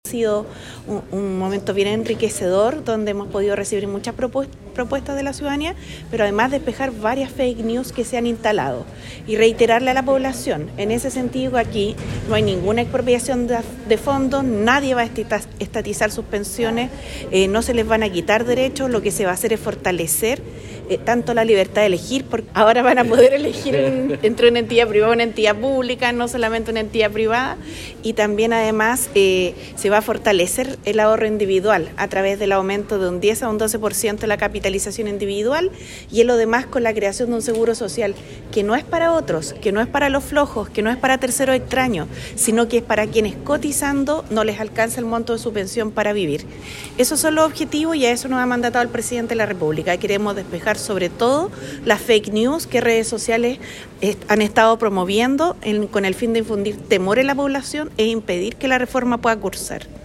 Ministros del Trabajo y Hacienda expusieron en el Teatro Municipal de Osorno
Del mismo modo la representante de la cartera de Trabajo indicó que es muy importante este tipo de actividades, pues se logra combatir las noticias falsas que existen en torno a la reforma de pensiones y los fondos pertinentes.